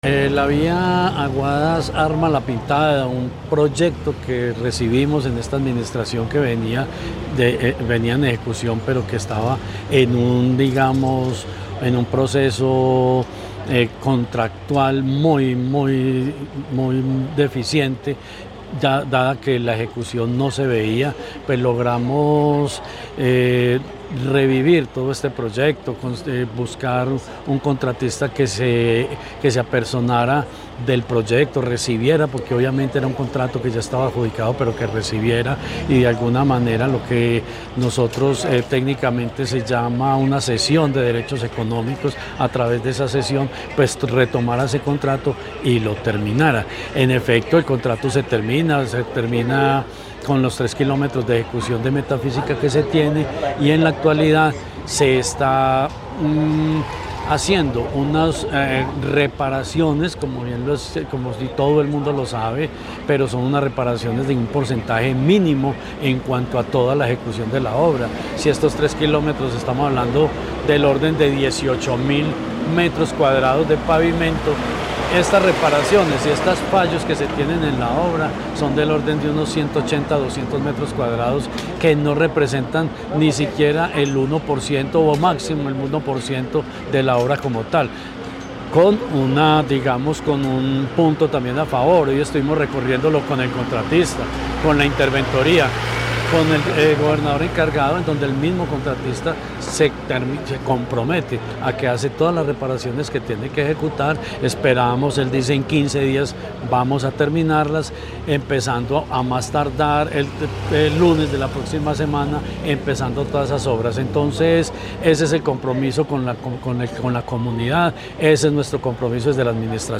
Secretario de Infraestructura, Jorge Ricardo Gutiérrez Cardona.